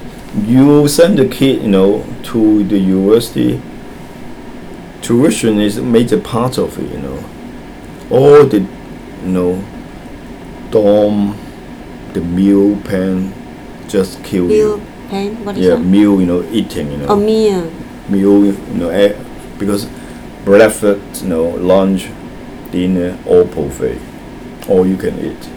S1 = Taiwanese female S2 = Hong Kong male Context: S2 is talking about the cost of tertiary education in America..
The [l] at the end of meal is pronounced as a vowel (it undergoes L-vocalisation), so the word is said as [mɪʊ] , and S1 hears it as view ; but perhaps the bigger problem is plan , in which the [l] is omitted and the vowel is not very open, so it sounds like [e] rather than [æ] .
Finally, add is pronounced with no [d] at the end, and S1 was unable to figure it out.